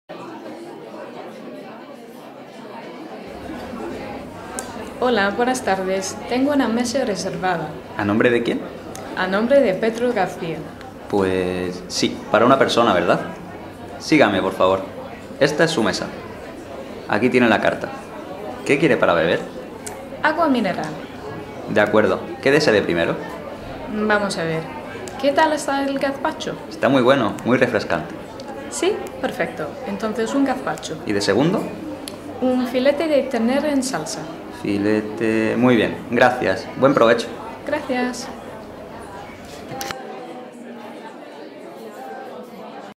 the whole dialogue try this instead.
ORDERING-FOOD-in-a-restaurant.mp3